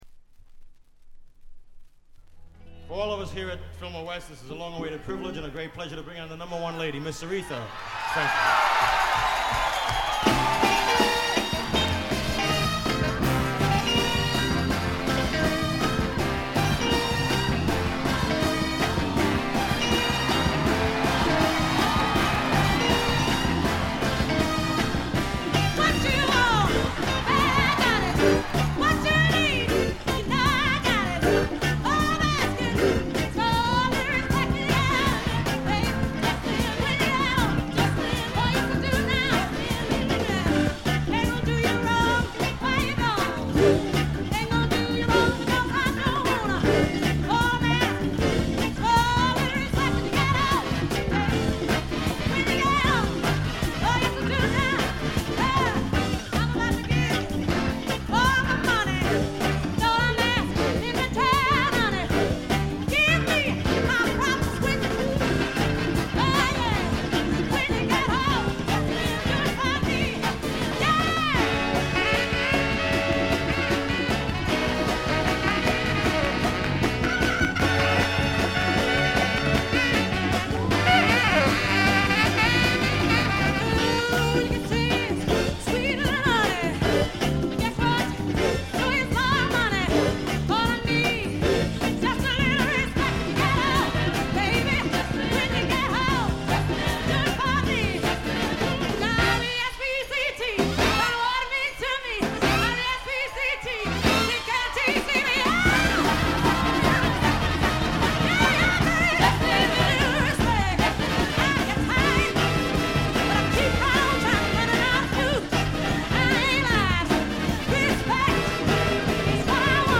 これ以外はほとんどノイズ感無し。
ソウル史上に燦然と輝く名作ライヴ。
試聴曲は現品からの取り込み音源です。